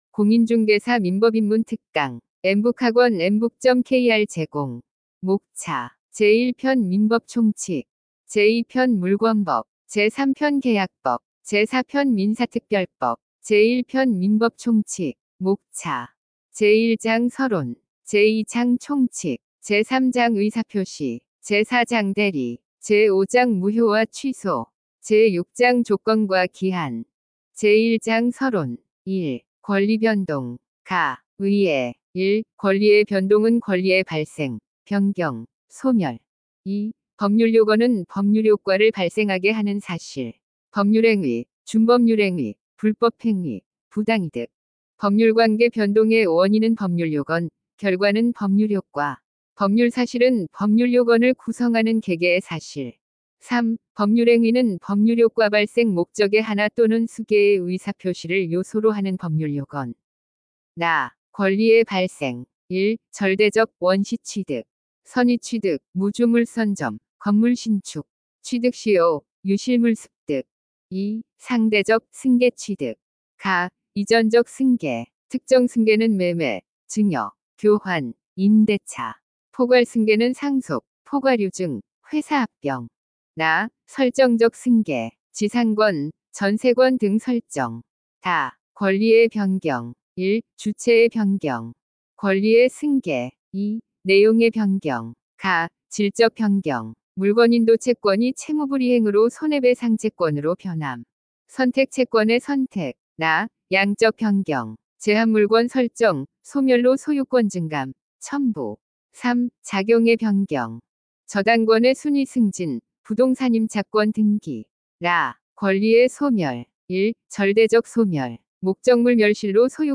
강사 : 인공지능(AI)
– 인공지능 성우 이용 오디오 강의
▶ 강의 샘플(4분)
정리한 내용을 TTS(Text to Speech) 기술을 이용해 인공지능 성우가 또렷한 음성으로 낭독해 귀로 들어도 98~99% 이해가 가능합니다.